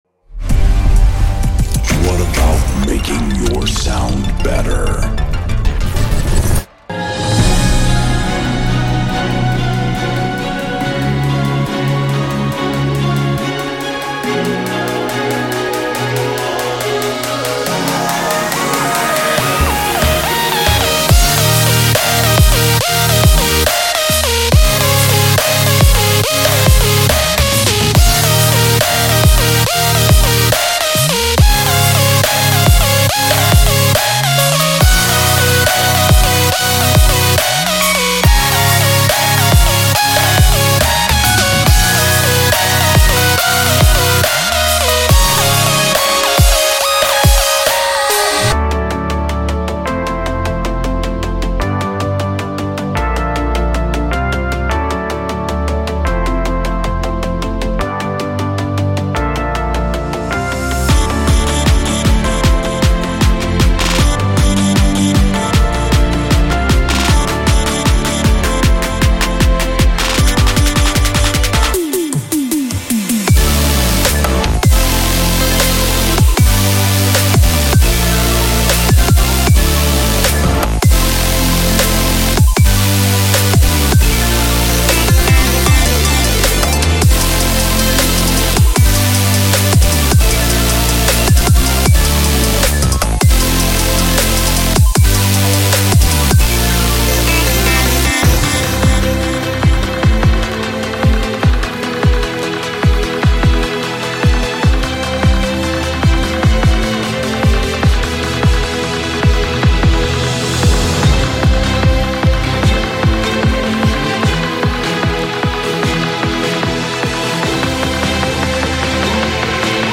享受琶音和弦，立管音高的微妙增加，芯片音调和软方波以及奇异的打击乐元素。
我们的声音清脆，新鲜，并模拟现场录制。从具有明亮金属光泽的踩hat到多种选择和定位的鼓声。
30 Percussive Loops (Acoustic, Cymbals, Percussion)